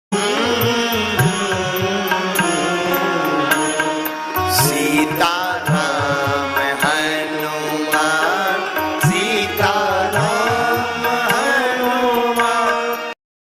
• Quality: High / Clear Audio
• Category: Devotional / Bhajan Ringtone